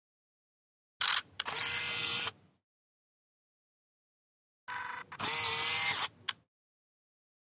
Camera Digital Turn On, Off x2